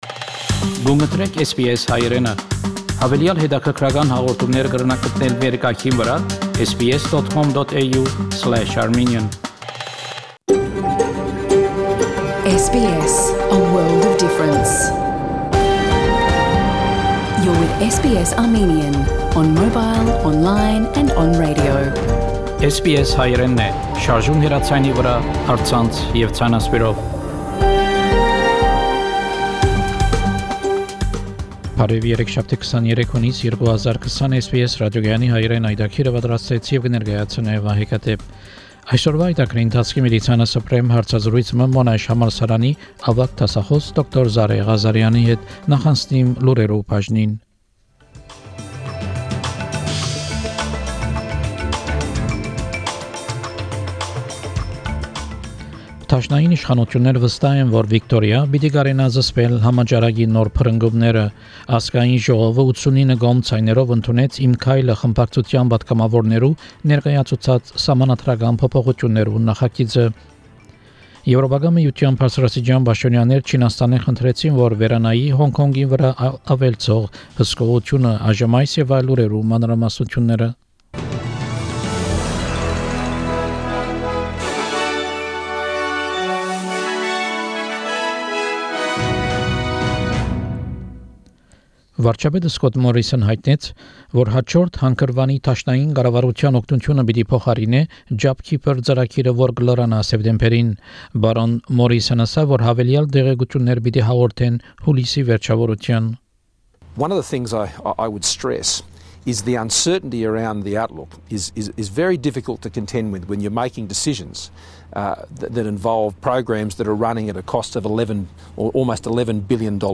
SBS Armenian news bulletin – 23 June 2020
SBS Armenian news bulletin from 23 June 2020 program.